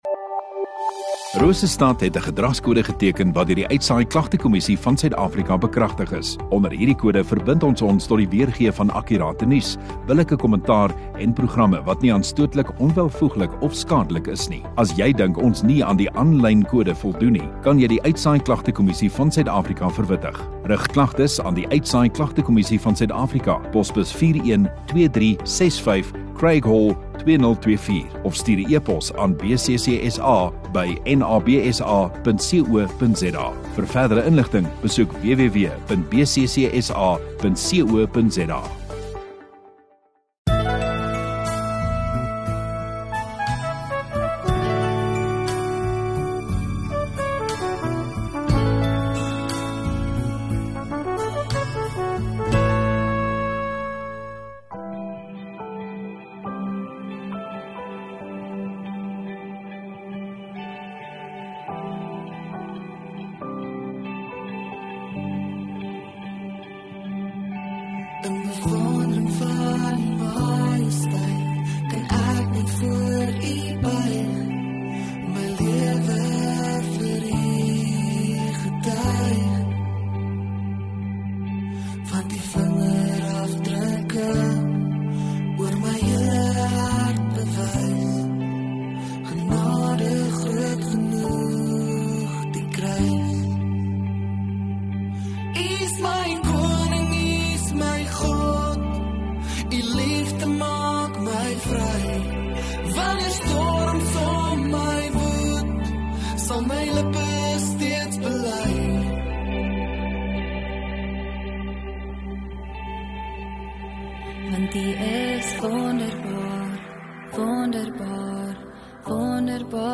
1 Dec Sondagaand Erediens